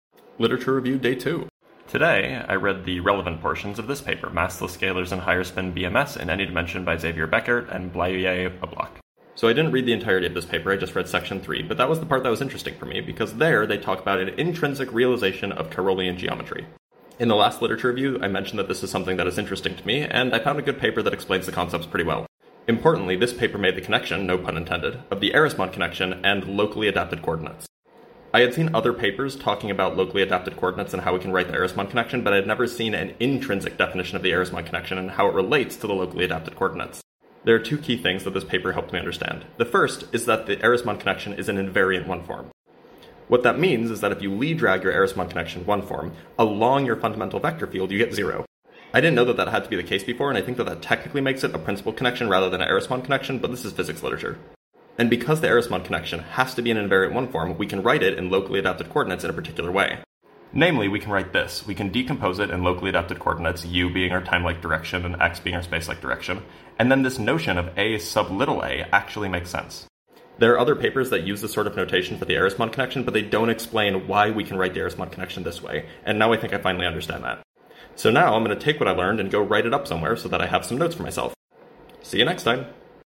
Massless Mp3 Sound Effect Literature review day 2: Massless scalars and higher-spin BMS in any dimension, by Bekaert and Oblak. Apologies for the background noise...